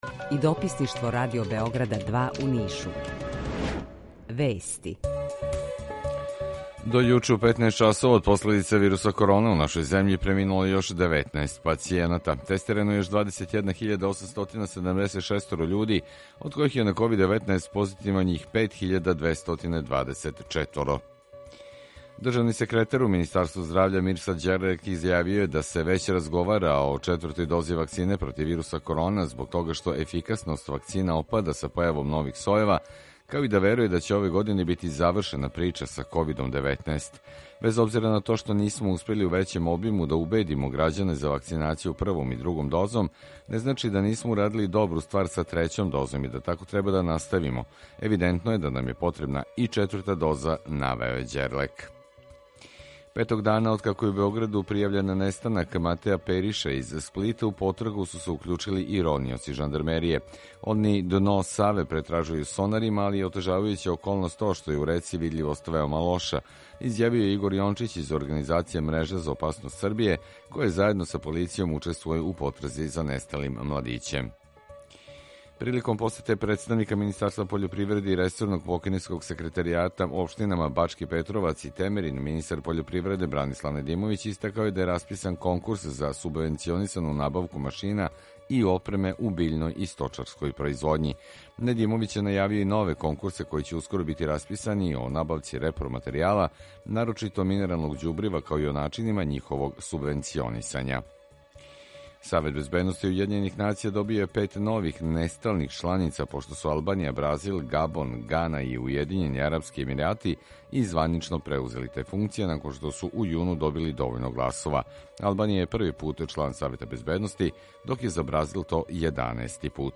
Укључење Kосовске Митровице
Јутарњи програм из три студија
У два сата, ту је и добра музика, другачија у односу на остале радио-станице.